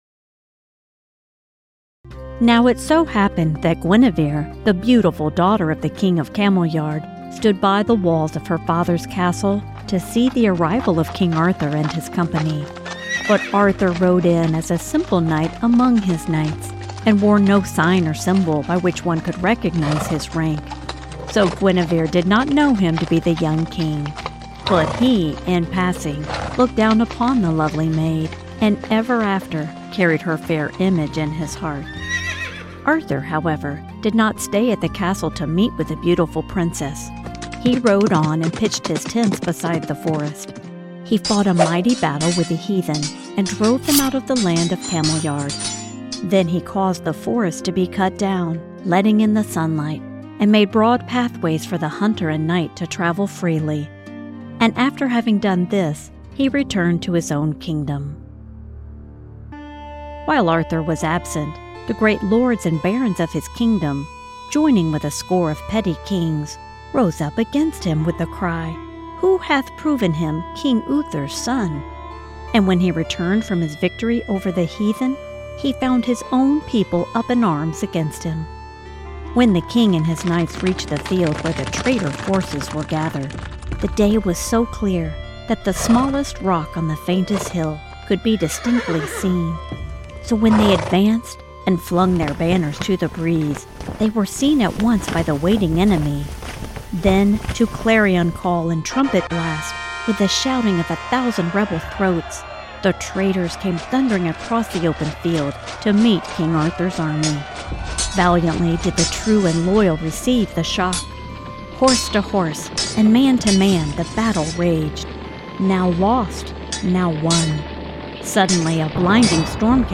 King_Arthur_AudioBk_Sample_From_Chapter_2.mp3